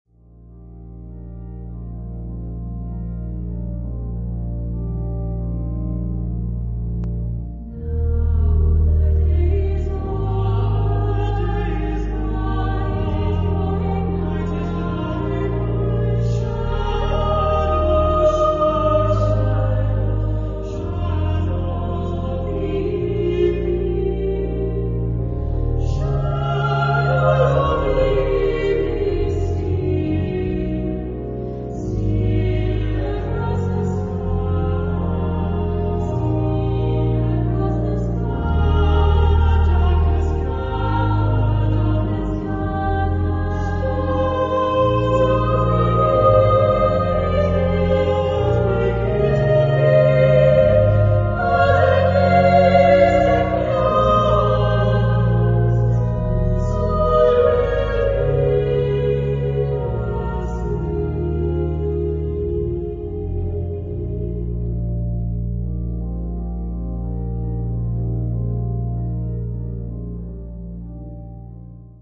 Genre-Style-Forme : Sacré ; Profane ; Chœur
Caractère de la pièce : doux
Type de choeur : SS  (2 voix égales )
Instrumentation : Orgue  (1 partie(s) instrumentale(s))
Tonalité : mi bémol majeur